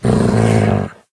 wolf_growl2.ogg